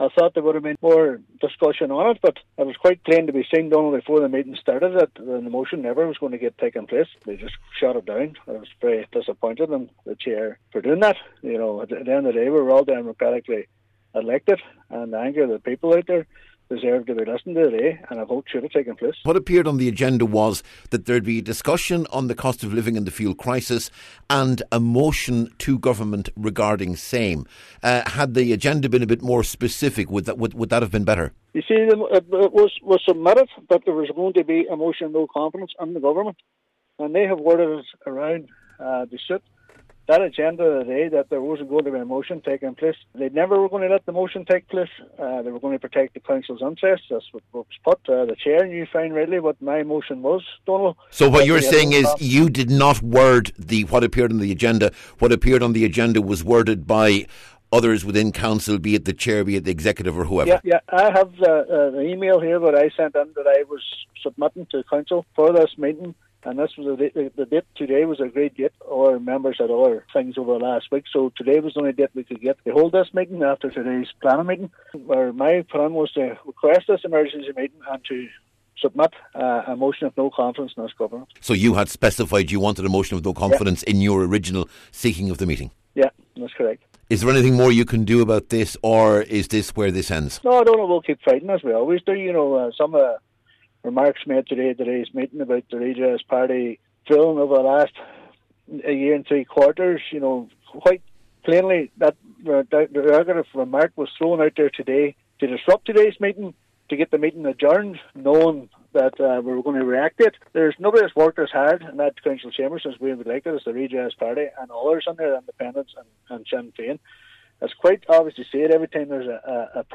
Cllr Devine says he’s disappointed but not surprised at how the afternoon panned out………..